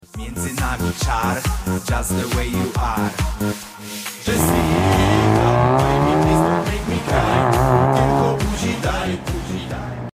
Subaru Impreza WRX STI (hawkeye) sound effects free download